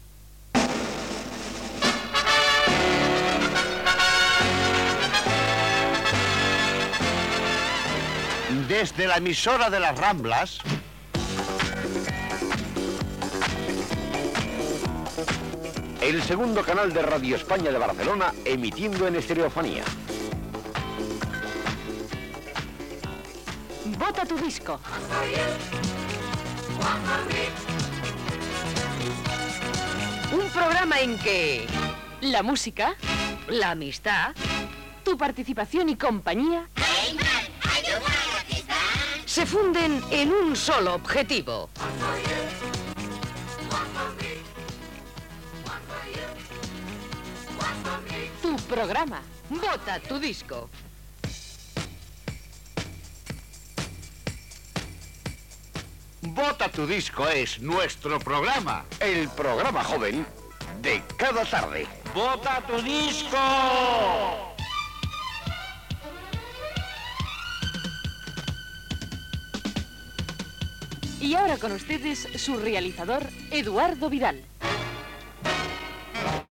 Careta del programa
Musical